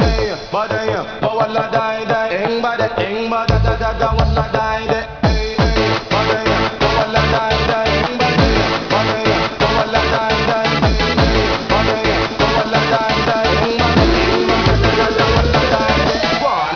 - A 16.75 second clip of a ragga beat techno song.